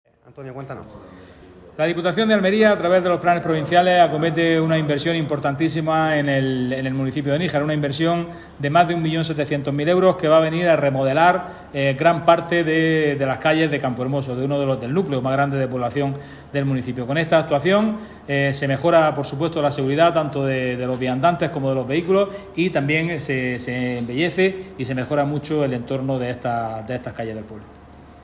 Diputado.mp3